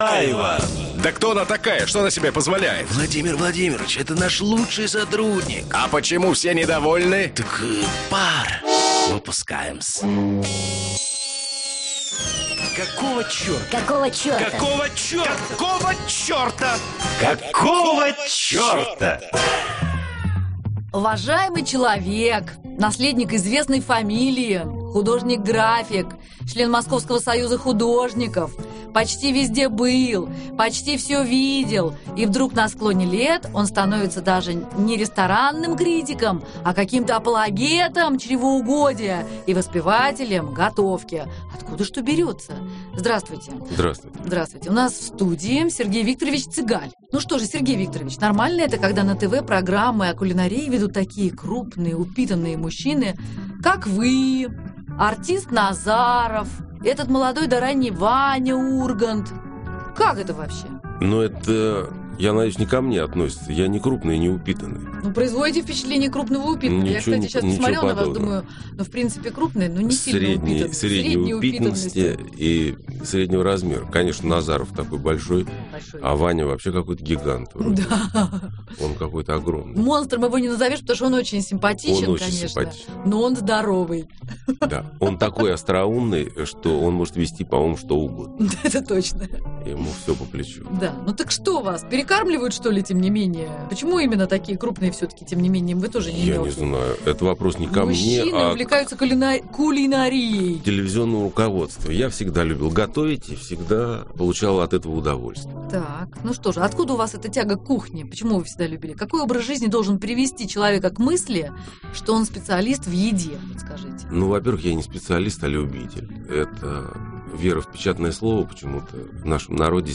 в эфире радиостанции "Эхо Москвы"